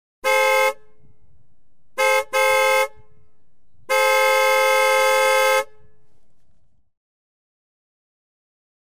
SFX汽车车外鸣笛急促音效下载
SFX音效